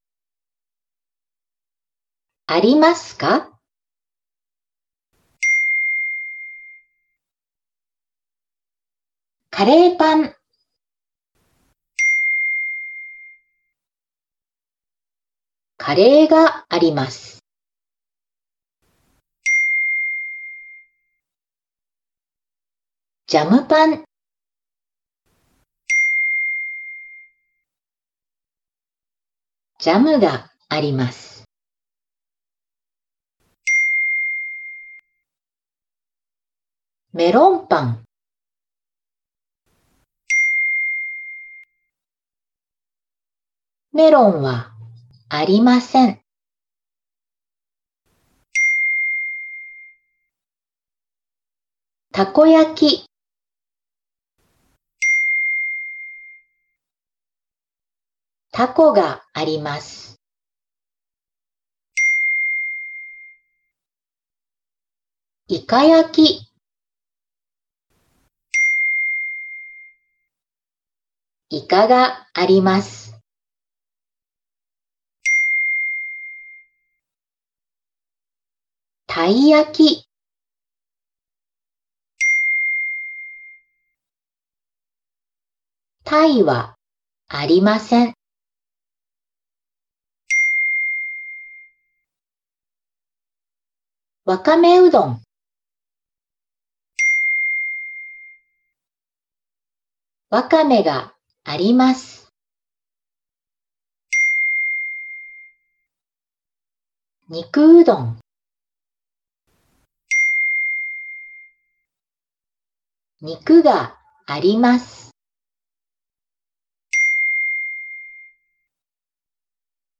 朗読音声付き